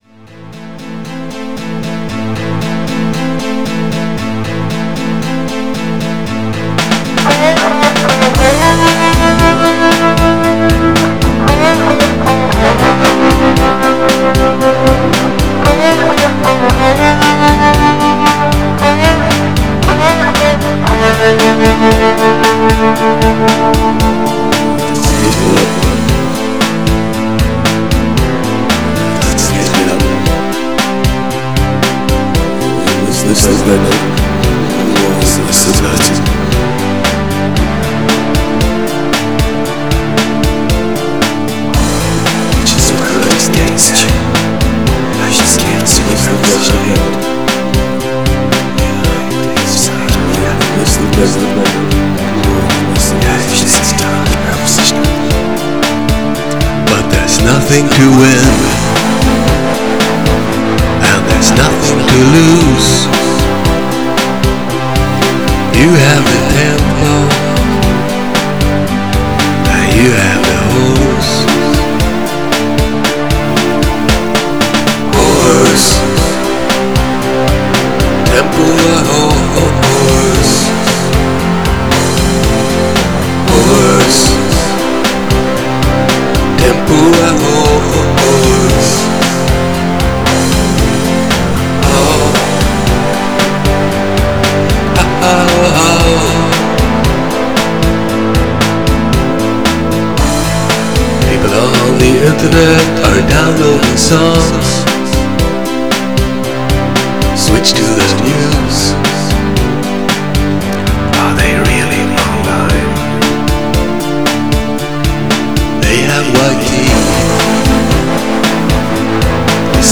Genre: Cumbia